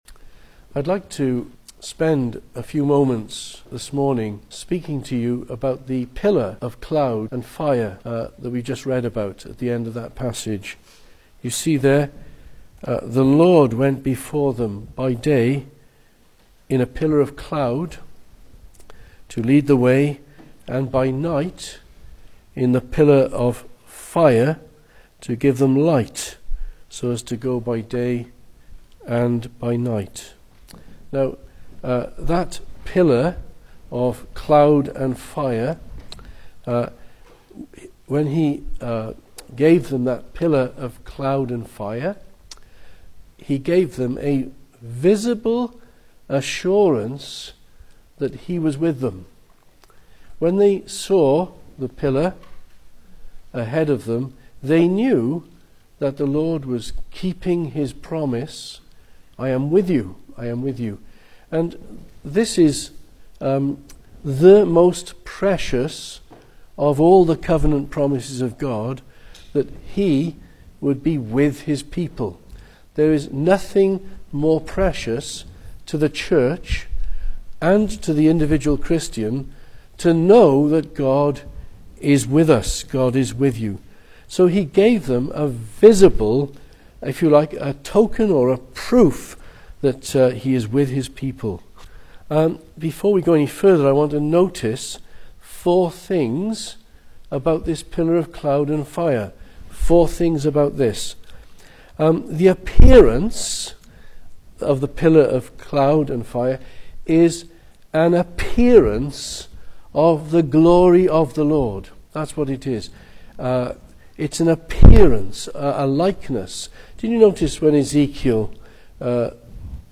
Service Type: Sunday Morning
Single Sermons